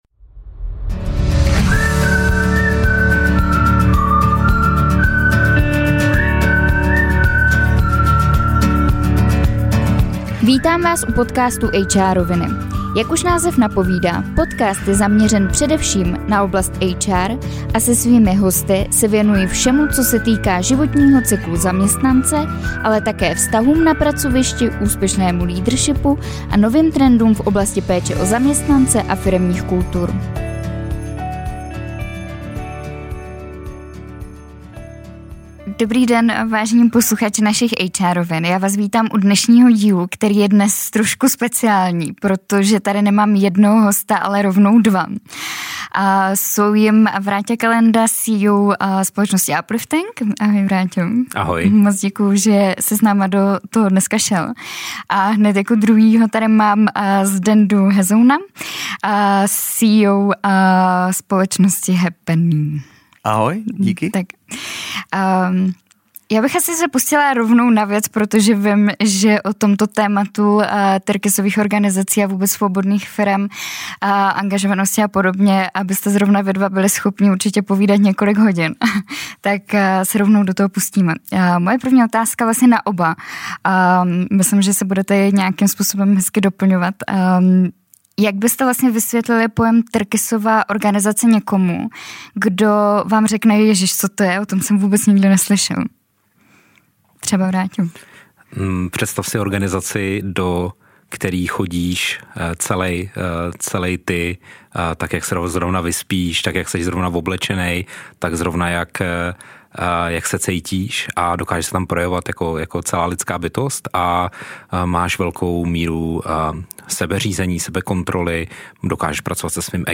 V dalším, speciální, díle jsme tentokrát přivítali rovnou dva hosty.